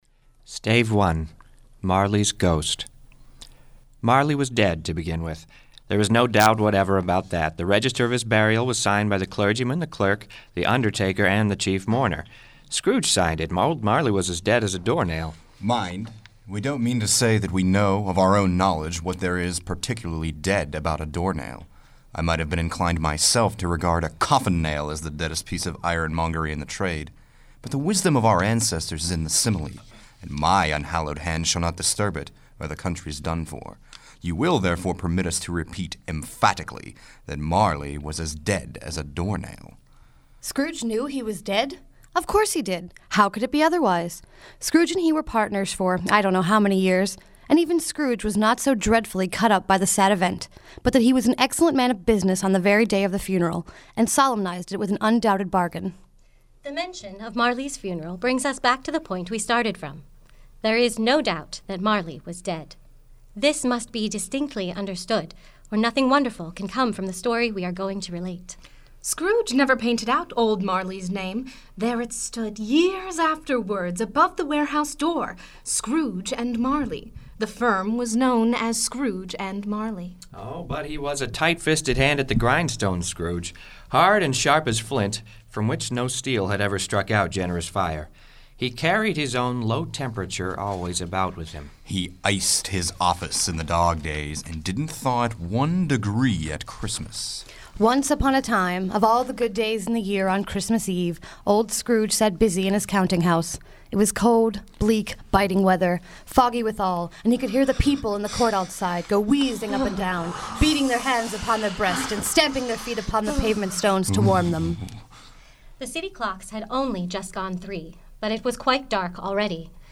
Live Performance: A Christmas Carol, Steel City Shakespeare Center